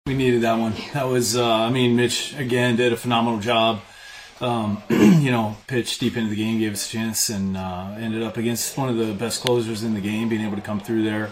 Manager Don Kelly says Mitch Keller gave them a chance to win, and the Pirates came through in the 9th.